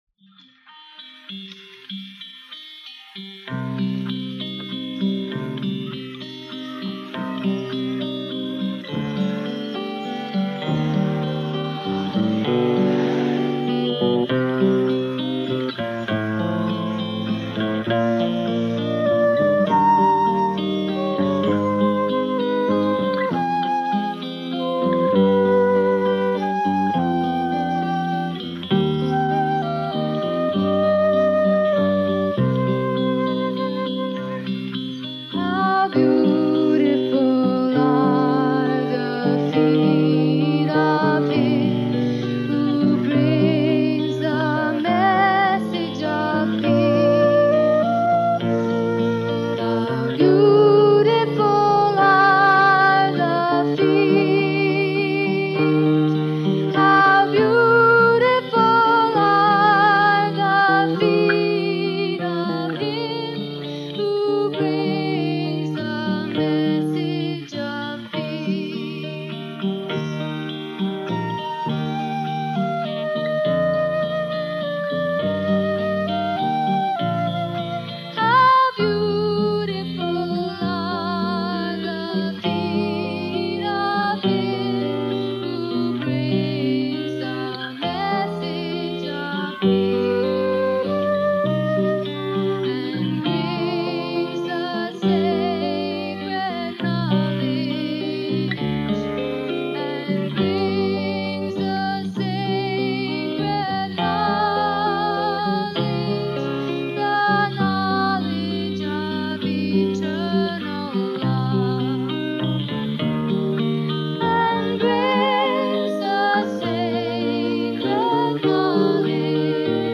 1980 BHAJANS RECORDED AT THE HOLI 1980 FESTIVAL